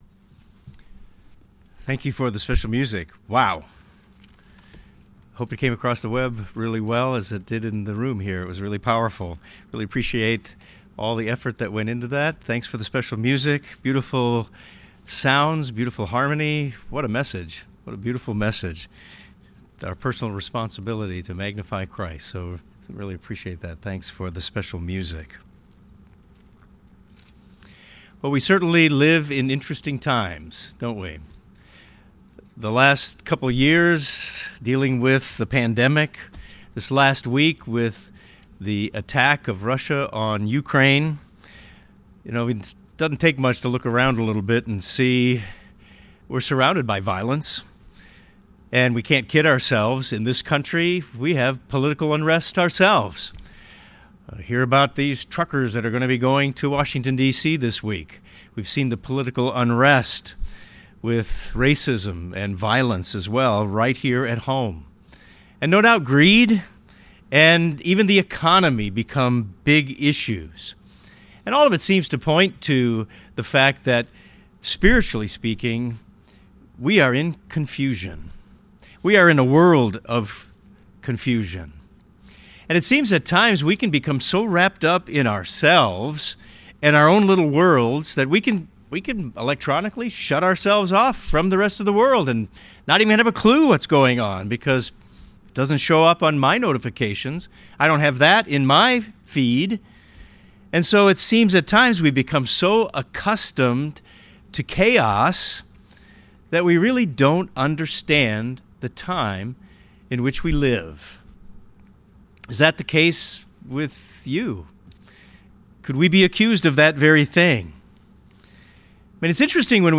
Jacob’s son Issachar and his family certainly faced changing and challenging times. This sermon will focus on lessons from their time to help us understand our time.